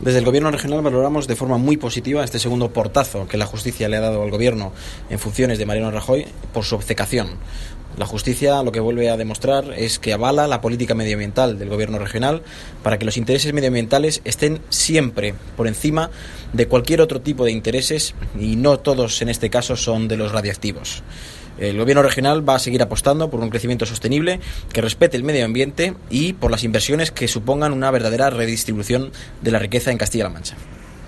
El portavoz del Gobierno regional, Nacho Hernando, ha afirmado